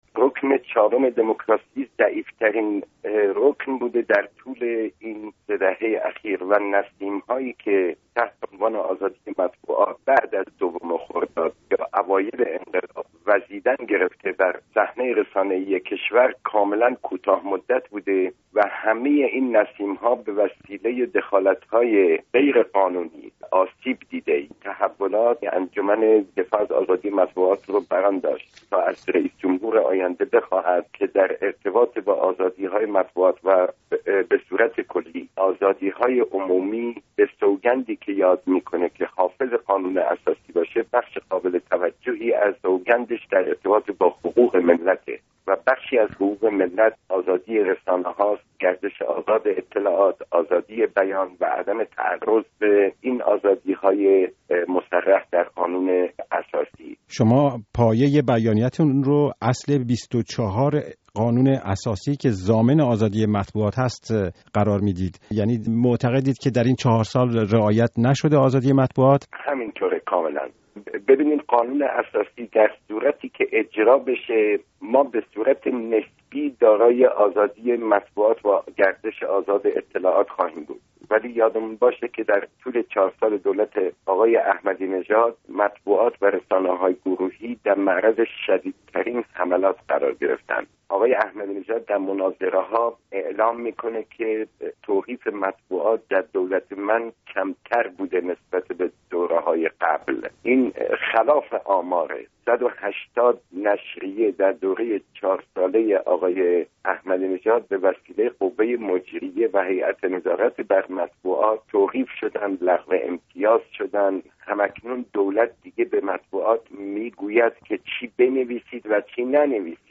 گفت‌و‌گویی رادیو فردا باماشاالله شمس الواعظین، سخنگوی انجمن دفاع از آزادی مطبوعات